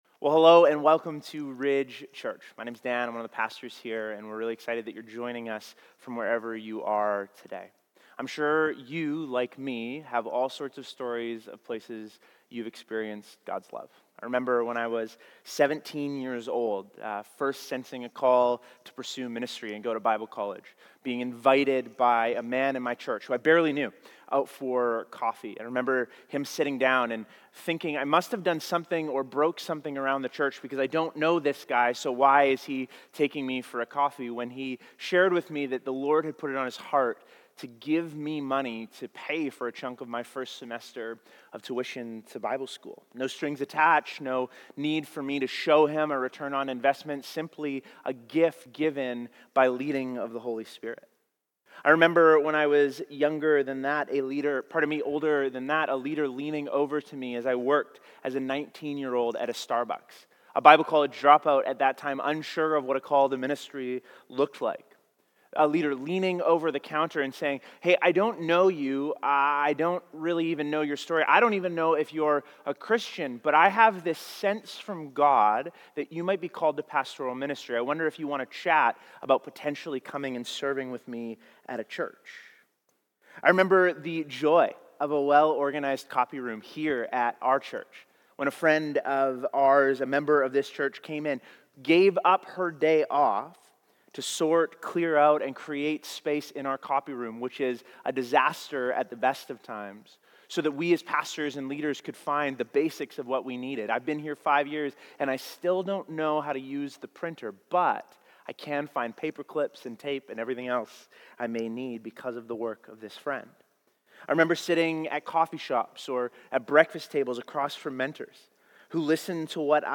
Sermons | Ridge Church